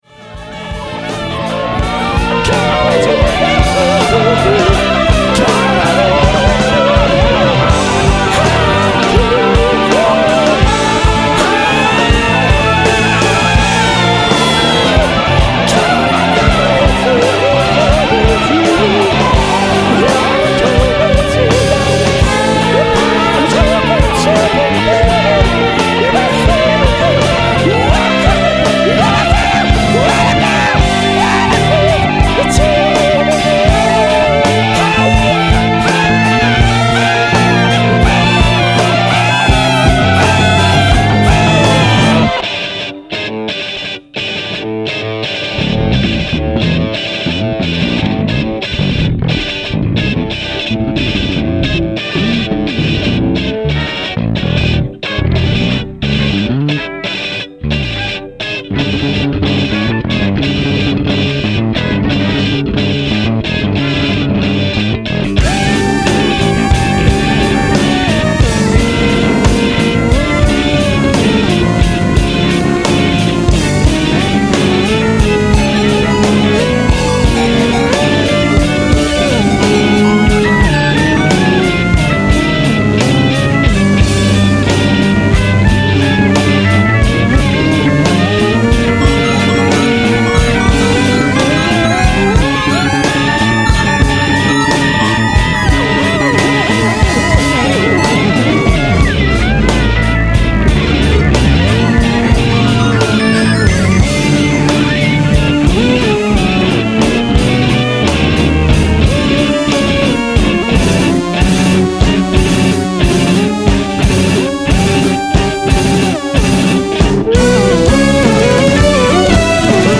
bass and vocals